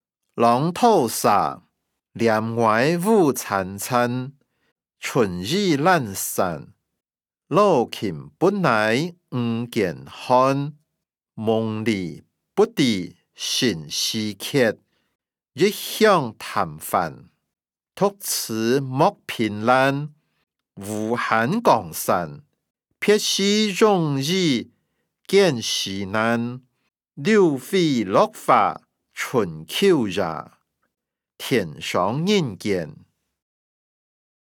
詞、曲-浪淘沙音檔(饒平腔)